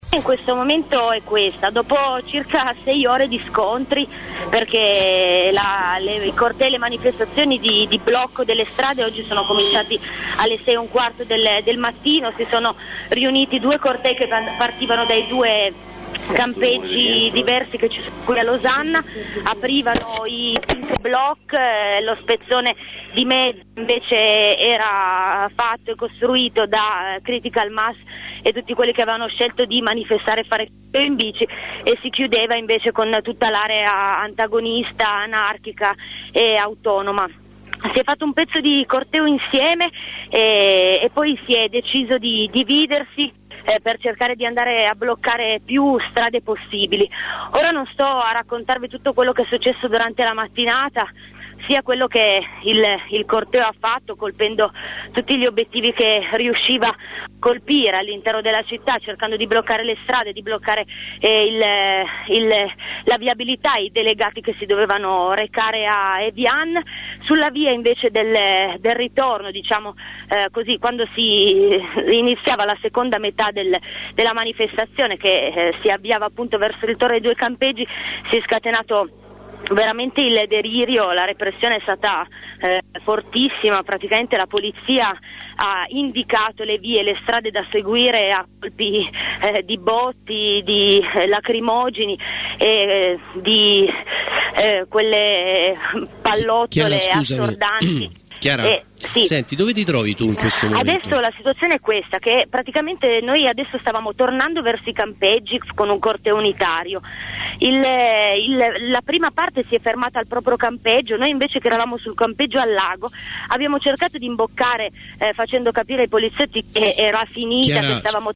Dal corteo
Dal corteo di Losanna, convocato per oggi in contemporanea a quello di Ginevra
blakoutdal_corteo.mp3